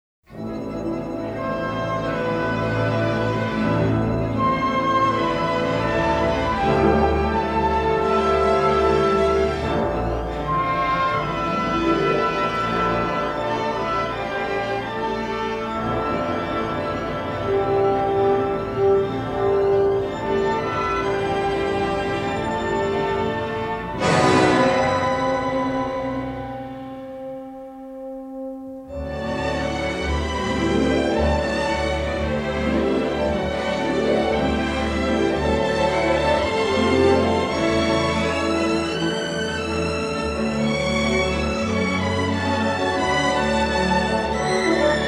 and a classic symphonic score.